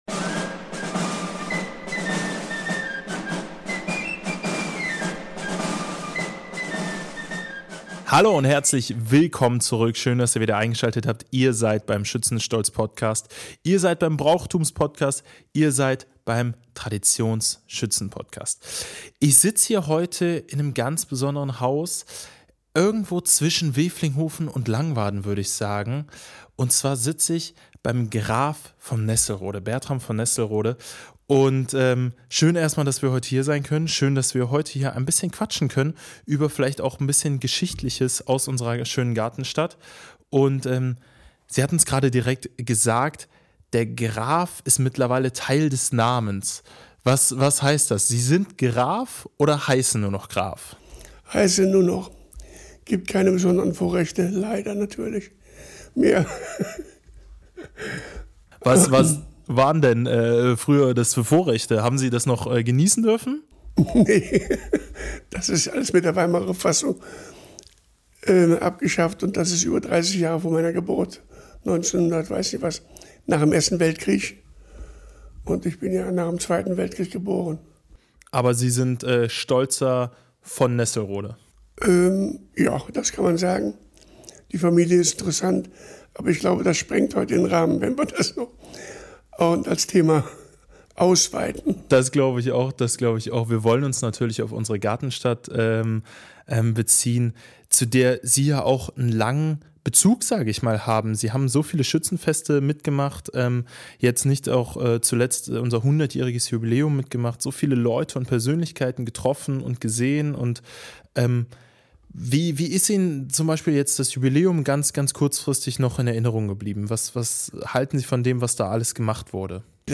Beschreibung vor 11 Monaten Wir sprechen mit Bertram Graf von Nesselrode, langjähriger Unterstützer des Schützenwesens und ehemaliger stellvertretender Bürgermeister von Grevenbroich, erzählt von seiner tiefen Verbundenheit zum Bürger-Schützen-Verein Wevelinghoven und seiner Faszination für den Fackelbau. In diesem Gespräch gewährt er spannende Einblicke in die Bedeutung von Schützenfesten und die Herausforderungen, Tradition und Zeitgeist zu verbinden. Er berichtet über seine Rolle in der Kirmesgesellschaft Einigkeit Langwaden, seinen Blick auf das Jubiläumsjahr des Wevelinghovener Vereins und seine persönliche Geschichte im Schützenwesen.